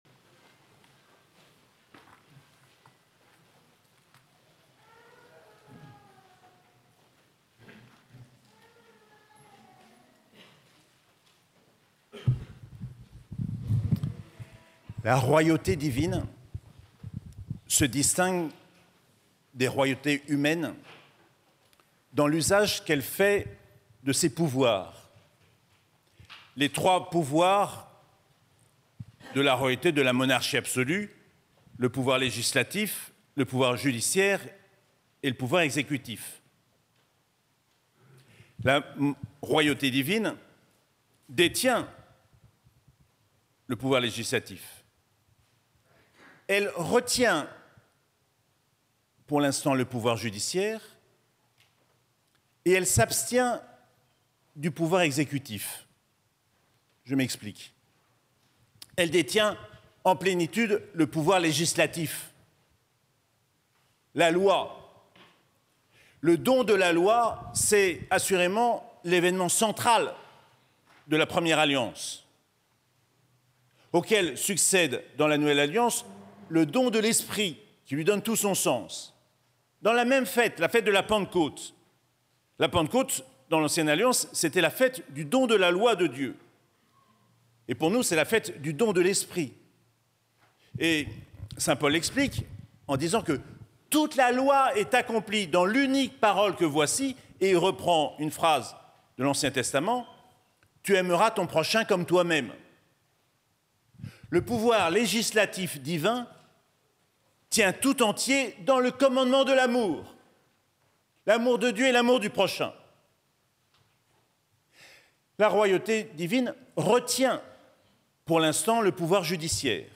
Solennité du Christ Roi - 24 novembre 2024